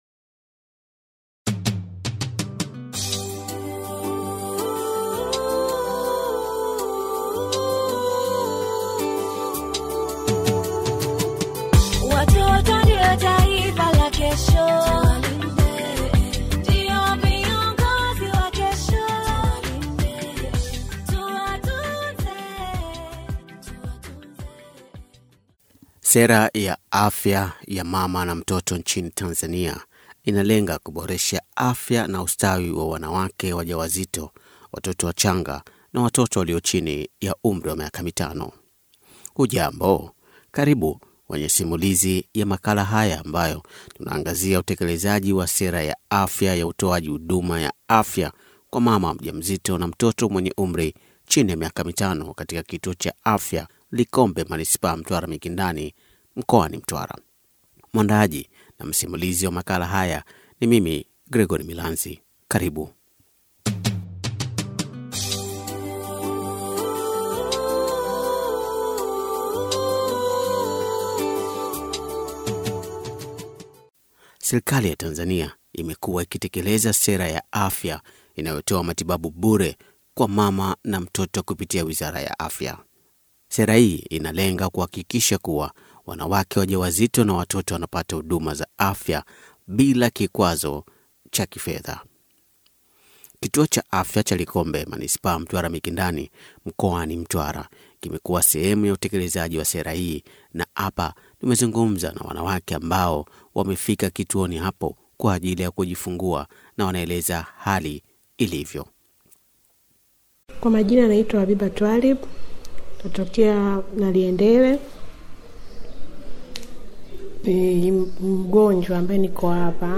Huduma bure ya mama mjamzito na watoto wachanga-Makala
makala-huduma-bure-ya-mama-mjamzito-na-watoto-wachanga-yatolewa-likombe.mp3